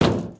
sounds / material / human / step / metall01gr.ogg